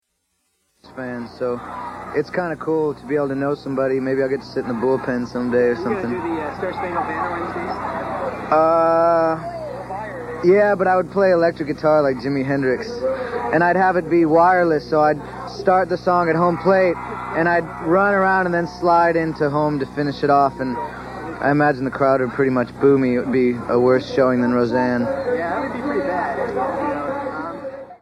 -- MTV interview, 9/20/92 [448K]